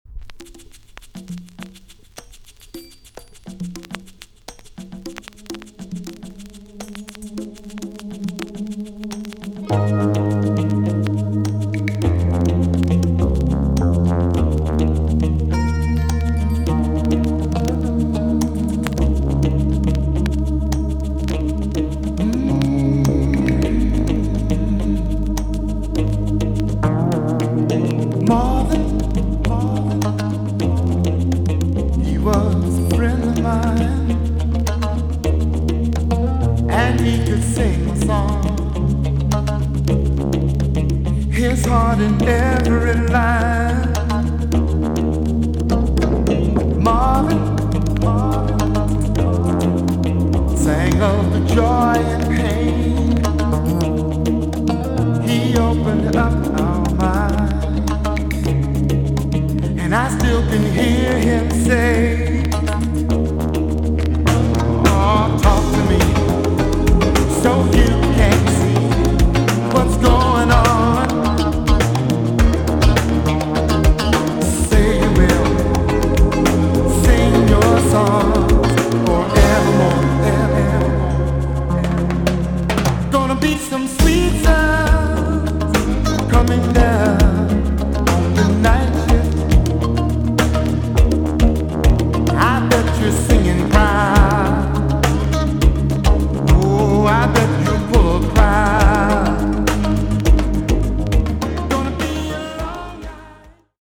VG+ 少し軽いチリノイズが入ります。